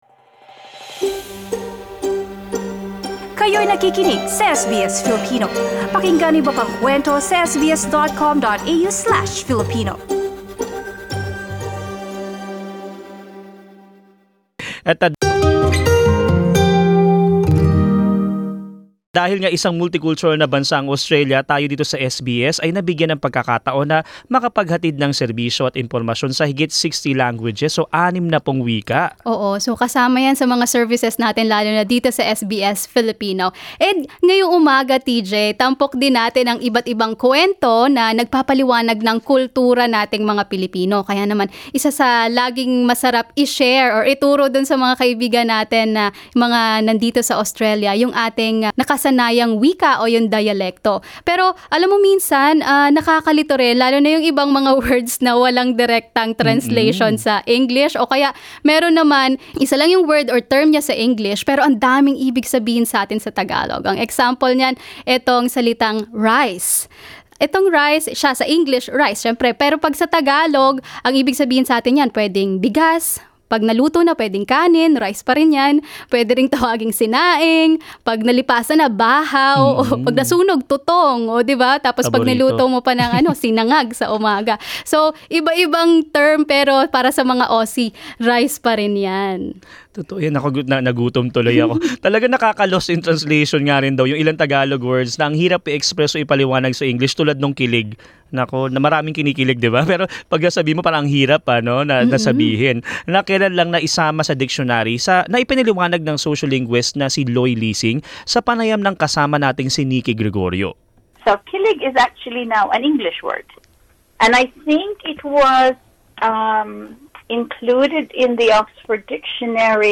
Pakinggan ang usapan ukol sa mga ilang salita sa Pilipino na walang eksaktong translation sa wikang Ingles.